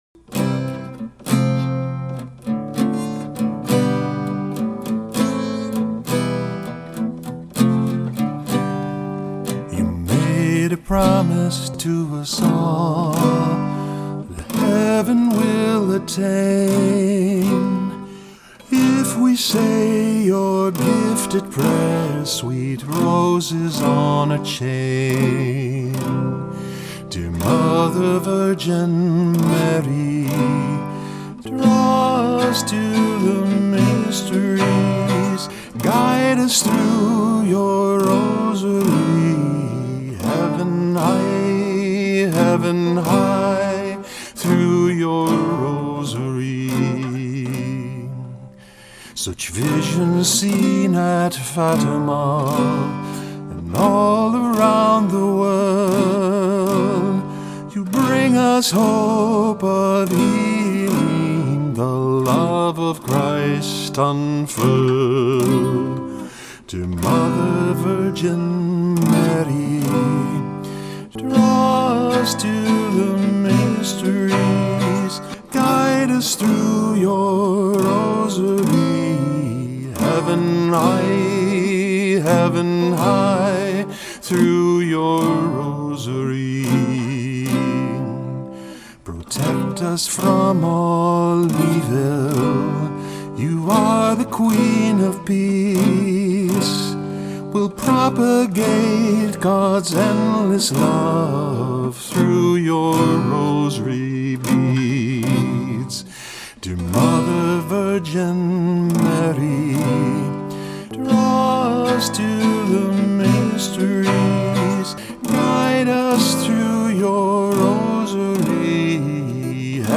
Here is a partial demo:
Here is my rough audio demo: